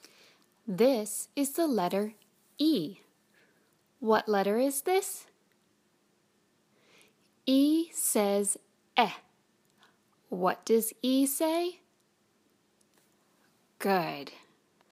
Letter Name/Sound
Letter E (short)